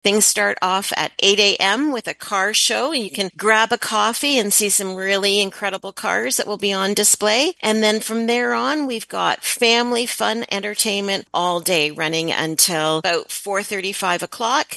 The annual celebration takes place at the Lucan Biddulph Community Memorial Centre, and as Mayor Cathy Burghardt-Jesson explains, the day kicks off bright and early with something for everyone.